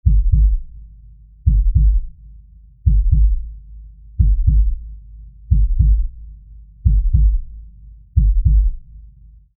دانلود آهنگ تپش قلب 2 از افکت صوتی انسان و موجودات زنده
دانلود صدای تپش قلب 2 از ساعد نیوز با لینک مستقیم و کیفیت بالا
جلوه های صوتی